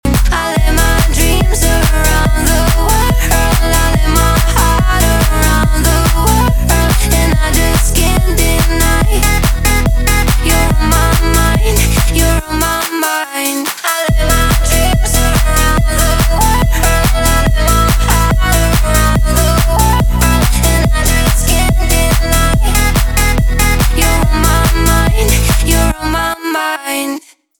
поп
битовые , басы , качающие